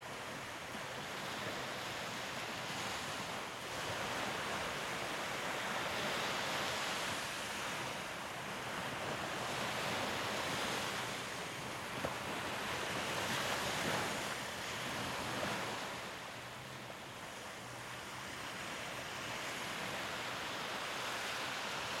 Звуки природы
Шум волн на песчаном пляже